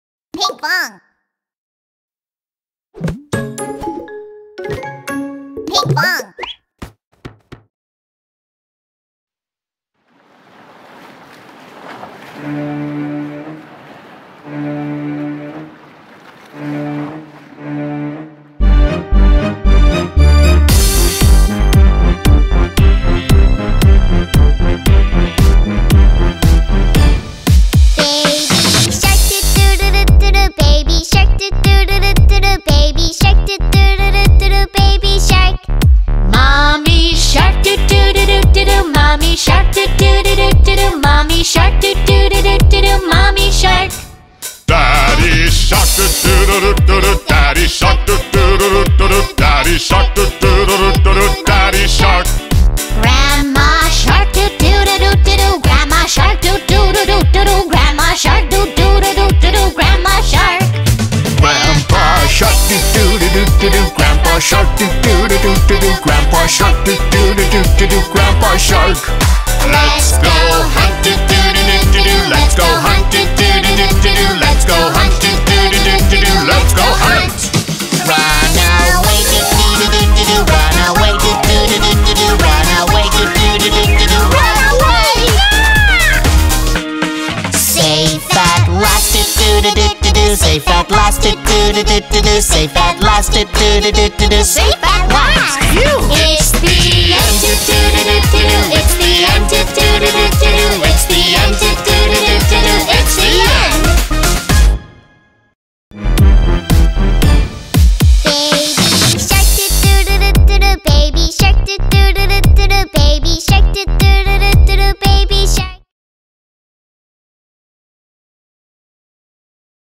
موسیقی کودک